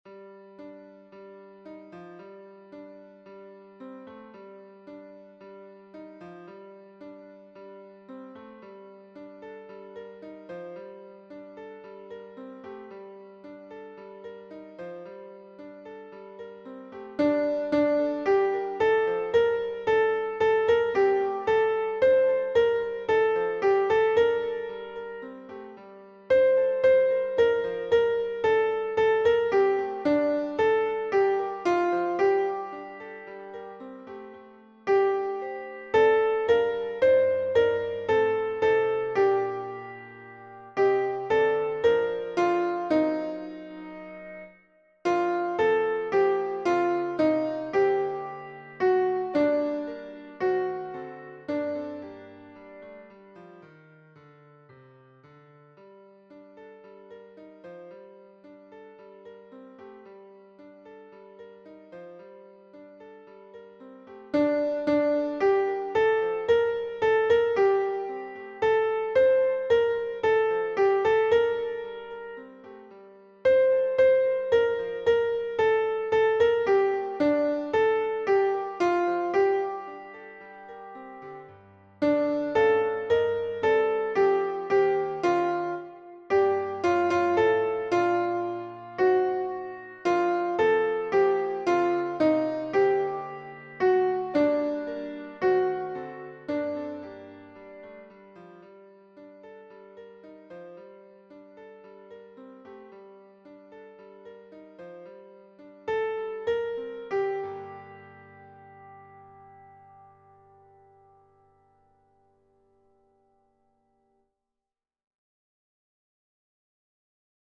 Alto Piano